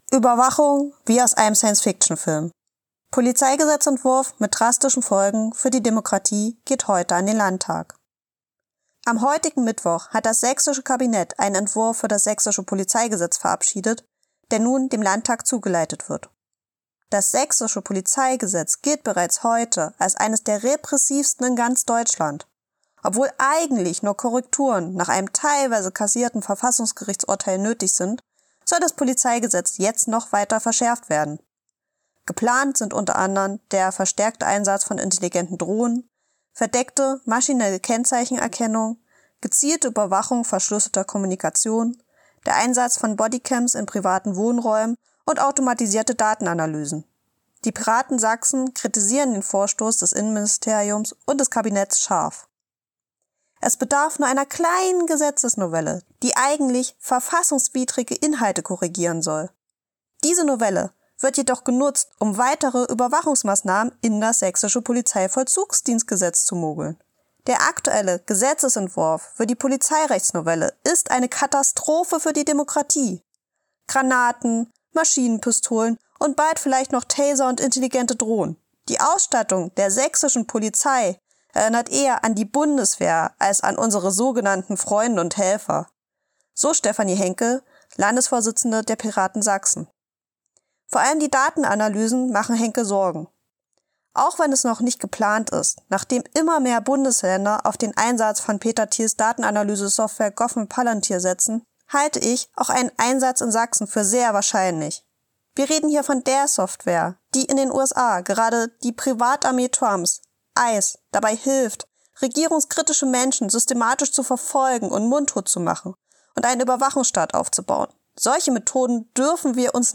Text als Hörfassung: ▶